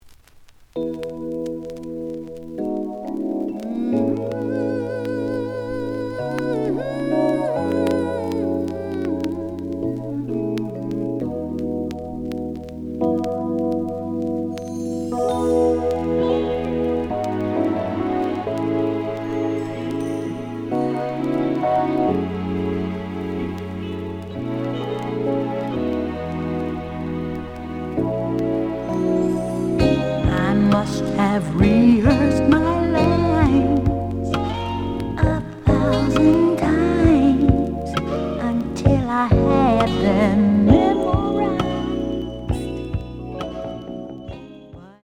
試聴は実際のレコードから録音しています。
●Genre: Soul, 80's / 90's Soul